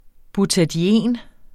Udtale [ butadiˈeˀn ]